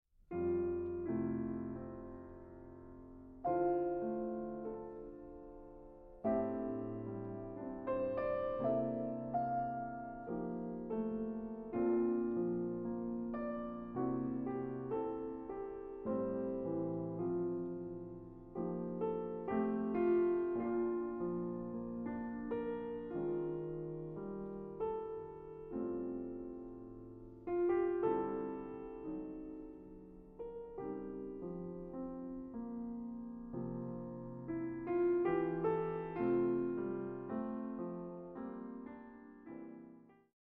Piano
Trackdown Studios